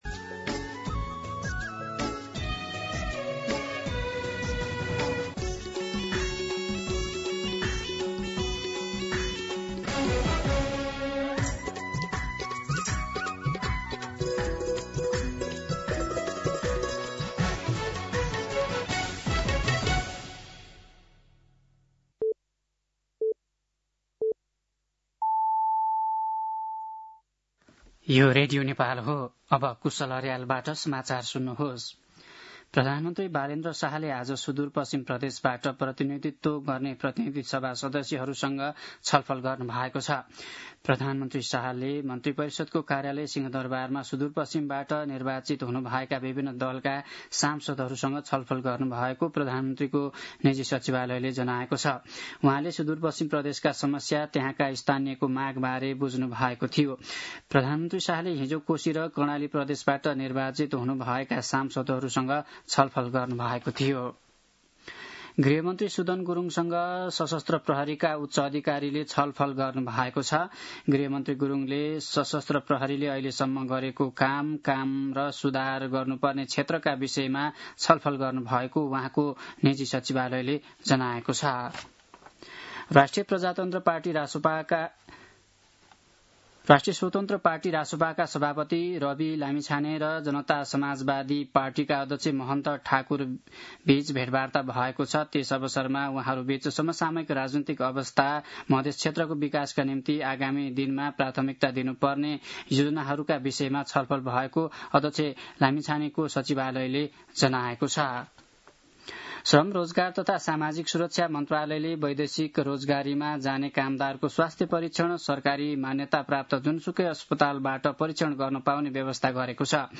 दिउँसो ४ बजेको नेपाली समाचार : १७ चैत , २०८२